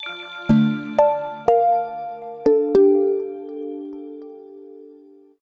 PowerOff.wav